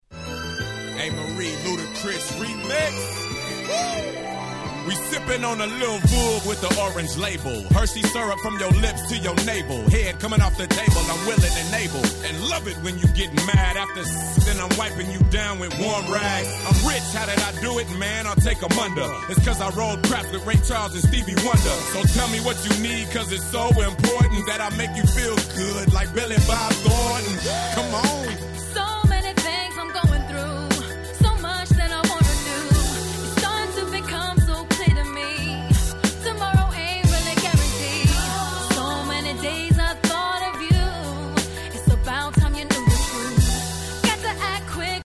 02' Smash Hit R&B !!
何て言うんでしょ、この『夕暮れ感』、最高に気持ち良いです。